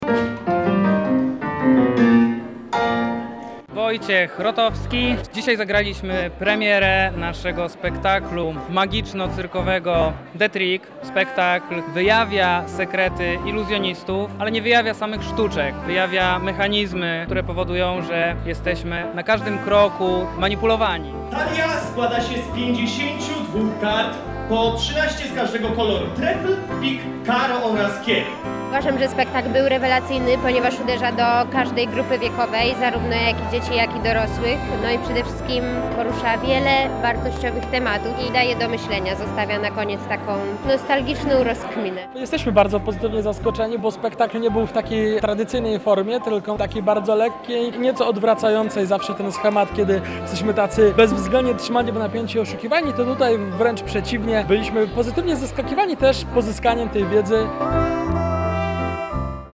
Nasza reporterka